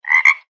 Frog-sound-ribbit.mp3